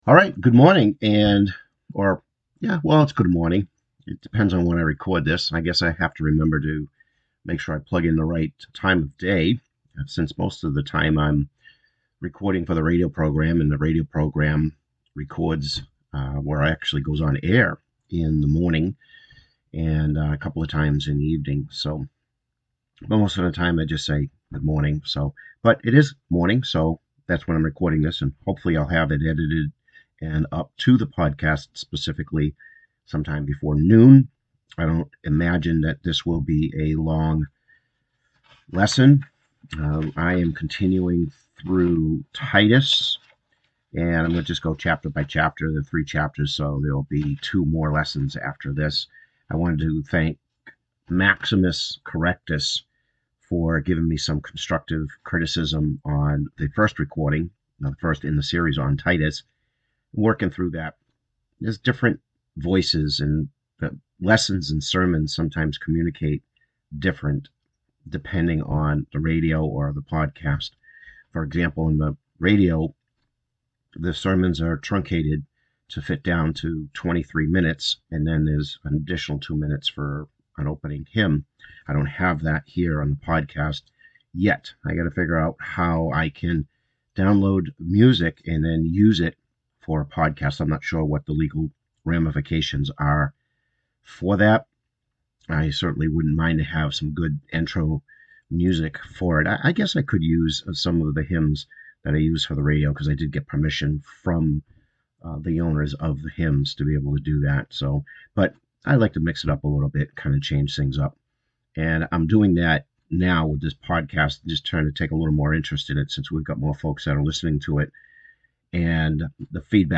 The sound quality is not that good. I forgot to connect the YETI mic to the system.